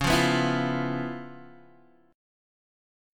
Db7#9 chord